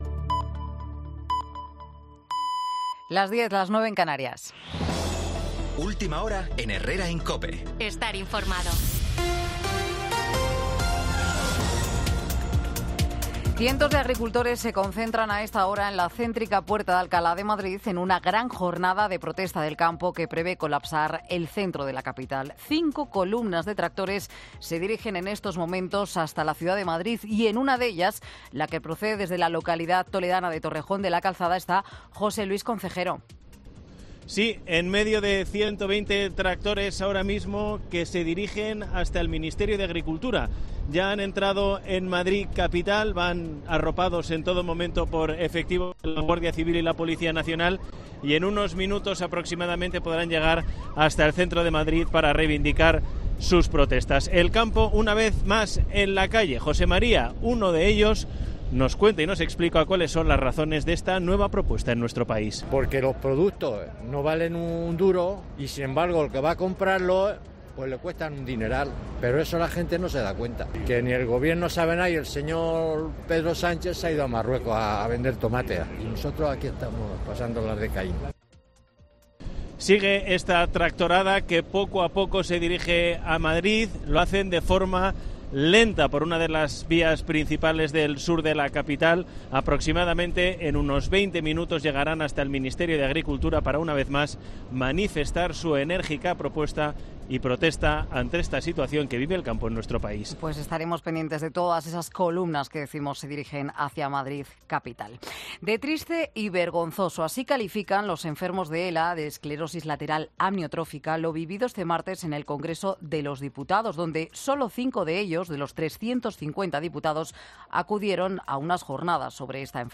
Boletín de Noticias de COPE del 21 de febrero del 2024 a las 10 horas